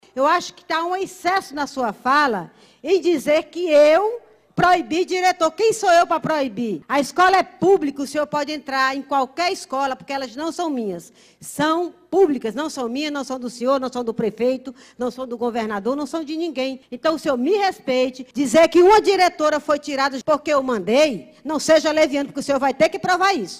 Os vereadores da Câmara Municipal de Manaus batem boca e trocam farpas durante sessão plenária nesta terça-feira, 27.